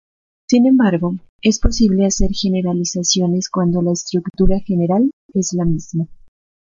po‧si‧ble
/poˈsible/